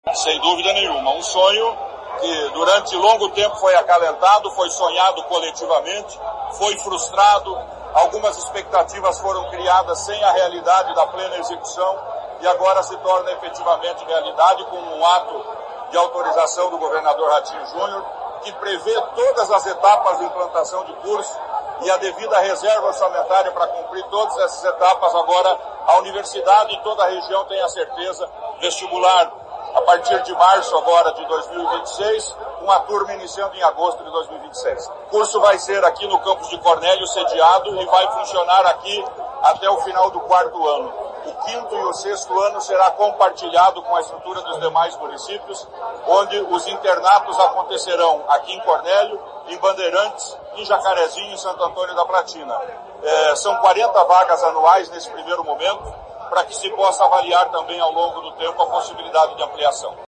Sonora do secretário da Ciência, tecnologia e Ensino Superior, Aldo Bona, sobre o curso de medicina na UENP